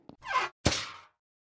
Among Us Going In Vent Sound Effect Free Download